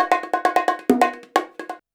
133BONG03.wav